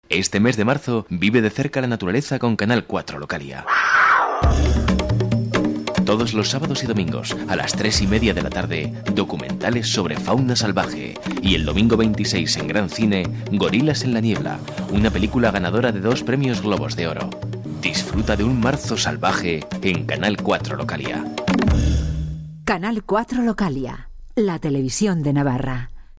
Sprecher spanisch für Werbung, Industrie, Imagefilme, E-Learning etc
Sprechprobe: Werbung (Muttersprache):
spanish voice over talent